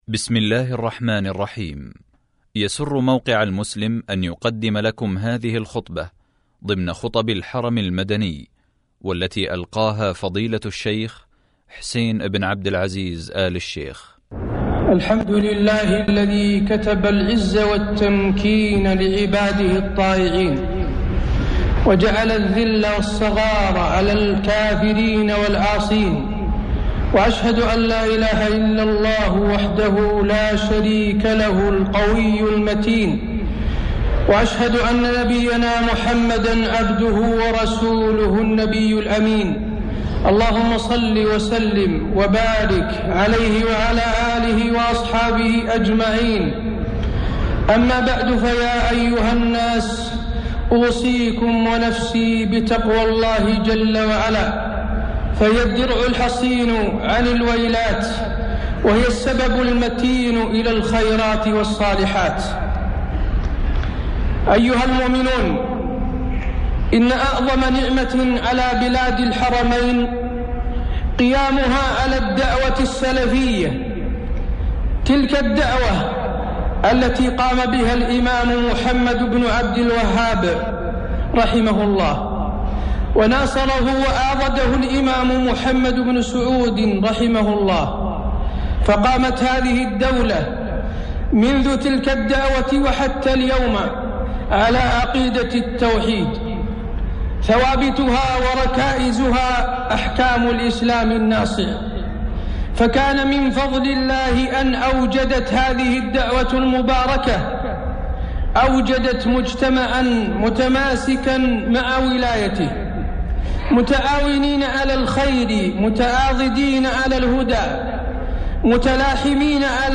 خطبة الجمعة 11/5/1432 هـ | موقع المسلم
خطب الحرم المكي